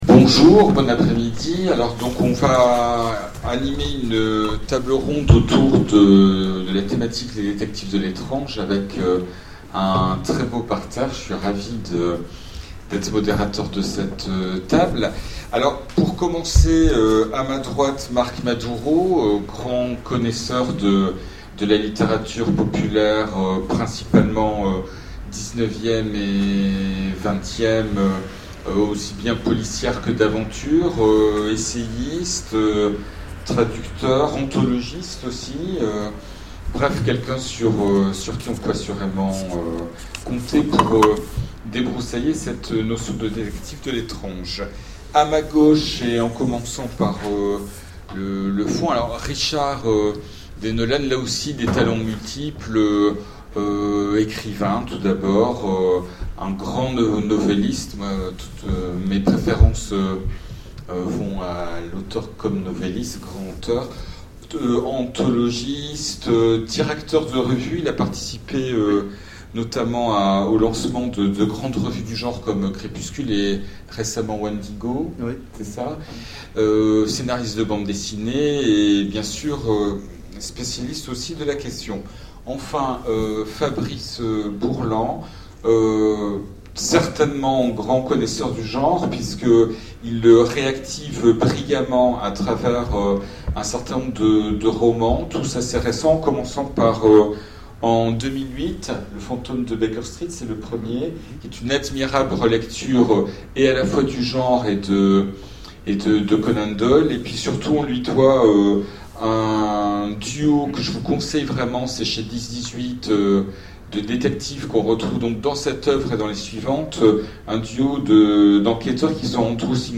Rencontres de l'Imaginaire de Sèvres 2011 : Conférence sur les détectives de l'étrange